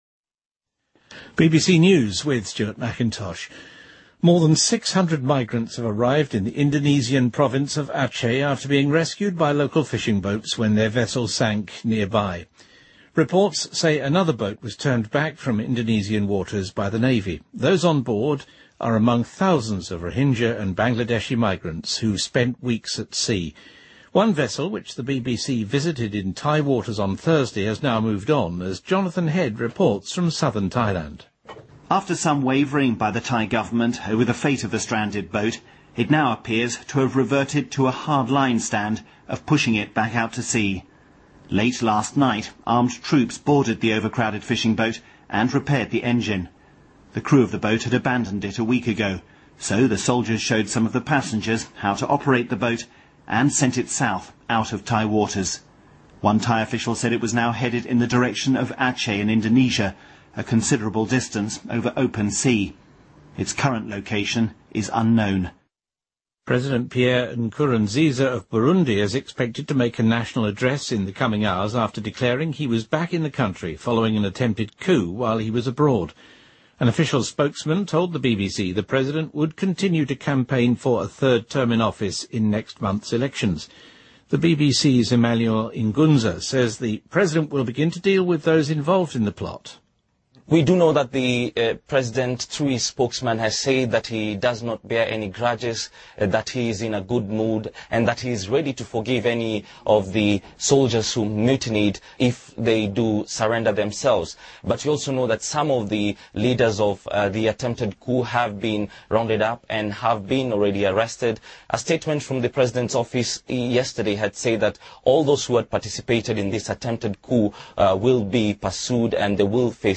BBC news,卢森堡首相让·贝特尔将与其备受瞩目的同性恋伴侣戴斯特尼结婚